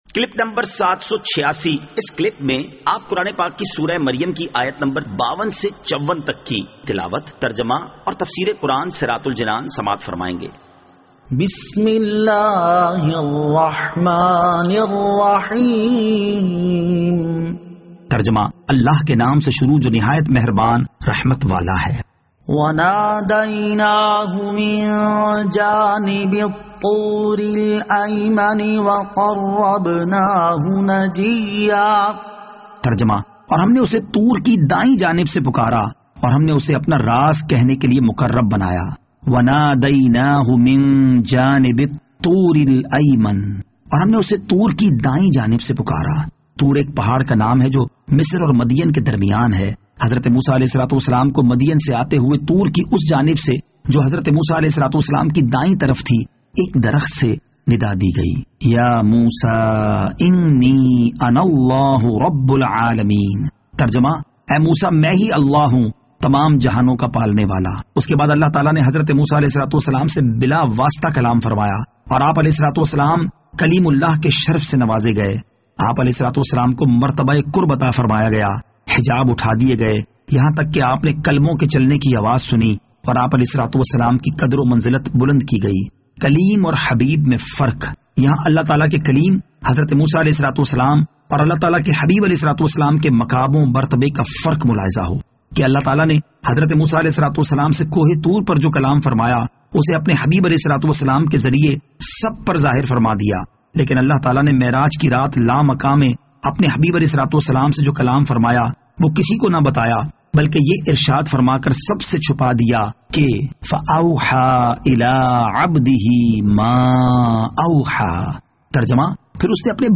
Surah Maryam Ayat 52 To 54 Tilawat , Tarjama , Tafseer